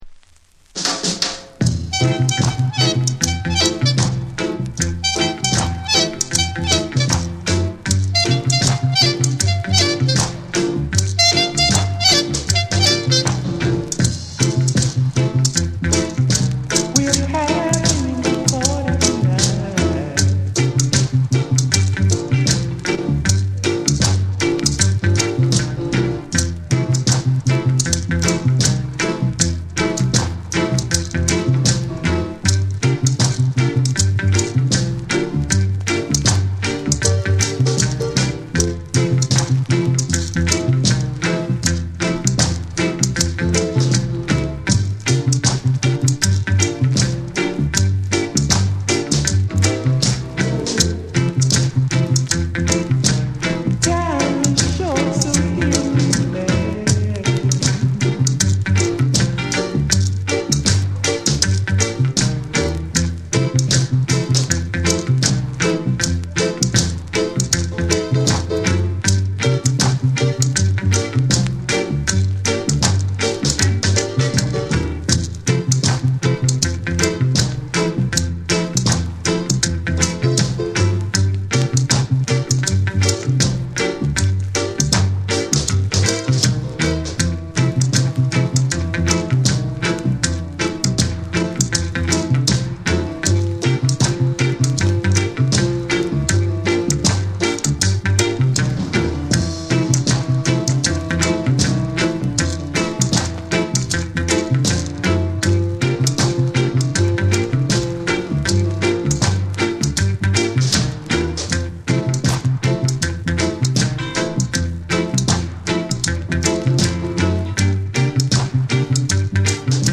温かみのあるコーラスワークの余韻を残しつつ、エコーやリバーブが深く空間に広がる王道ルーツ・ダブを展開。
じっくりと浸れるクラシックなダブ作品。
REGGAE & DUB